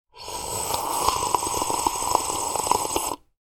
Tea Slurp Sound Effect: A Satisfying Sip of Hot Tea or Coffee
A person loudly sipping hot tea or coffee. Rudely drinking a liquid. Human sounds.
Tea-slurp-sound-effect.mp3